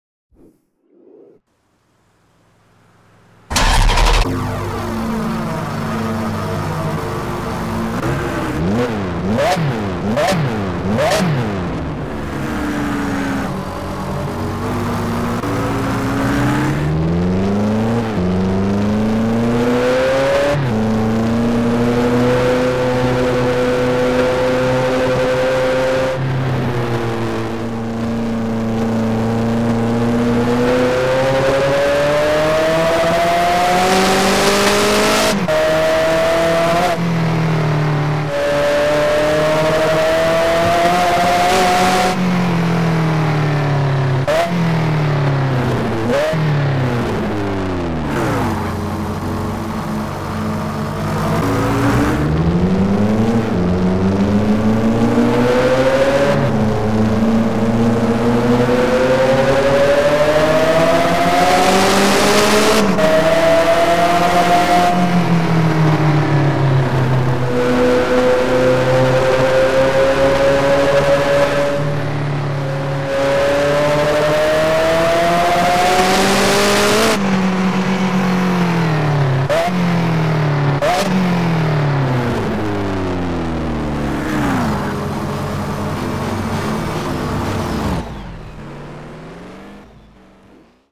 - Ferrari 430 Scuderia